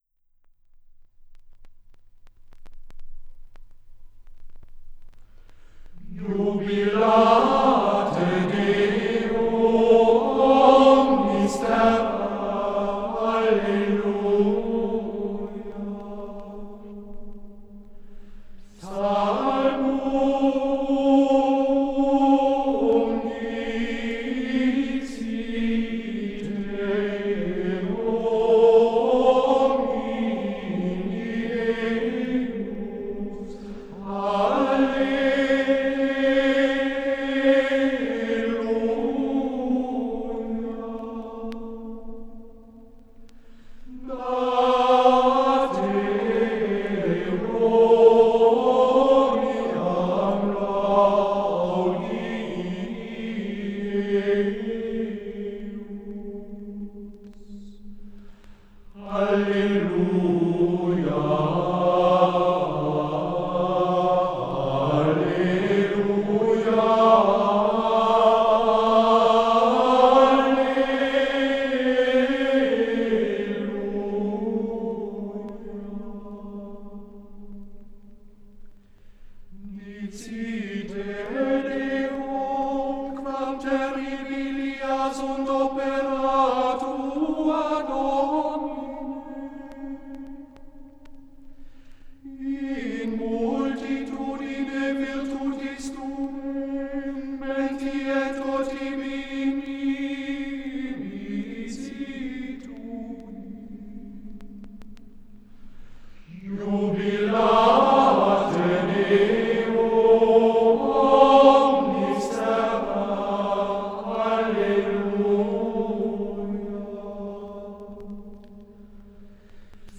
Introitus
Gesang: Schola gregoriana im Auftrag der Robert-Schumann-Hochschule Düsseldorf
aufgenommen in der Klosterkirche Knechtsteden